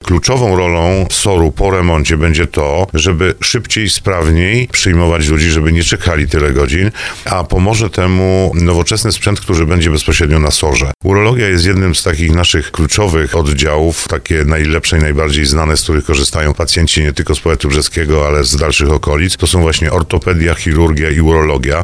– mówi starosta brzeski Andrzej Potępa.